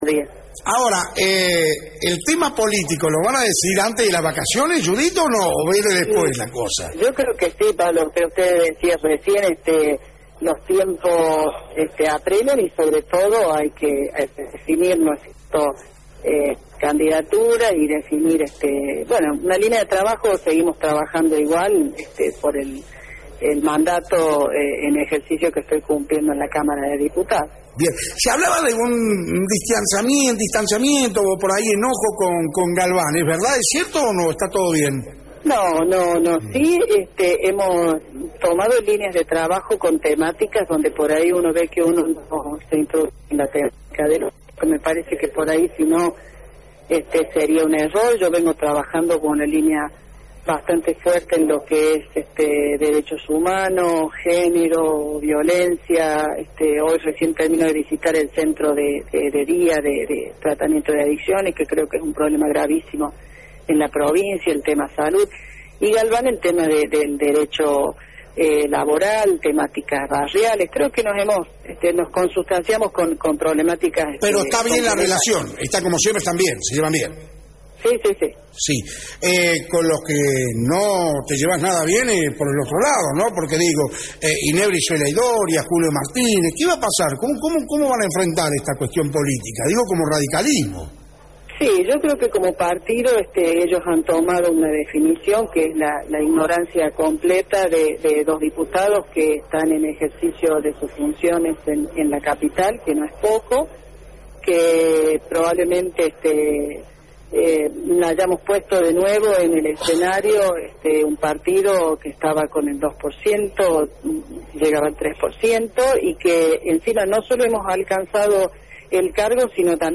Judit Díaz Bazán, diputada provincial, por Radio Fénix